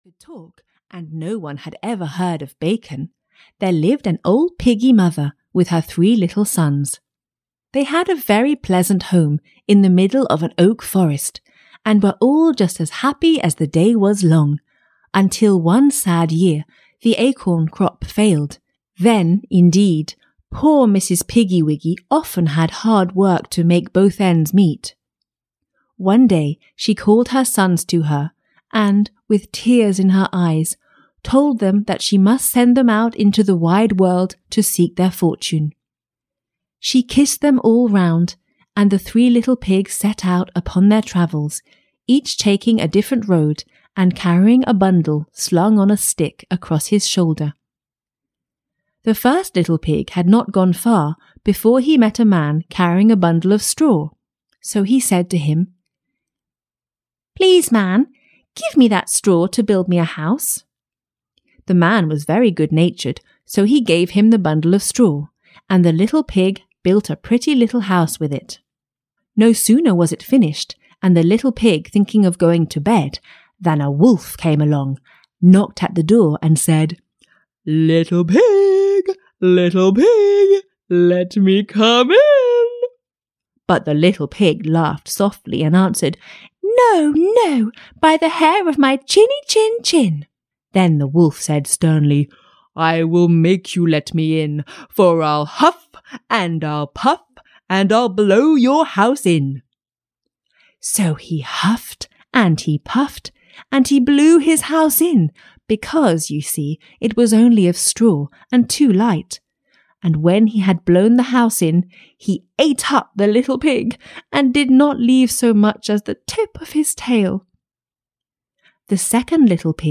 Ukázka z knihy
This audiobook presents you with the most famous and inspiring stories, narrated in a warm and lively way: The Three Little Pigs, Beauty and the Beast, Bluebeard by Charles Perrault, Snow Drop and the Seven Dwarves, The Frog Prince, Donkeyskin, The Little Match Seller, Tom Thumb, Ali Baba and the Forty Thieves, and Jack the Giant Killer.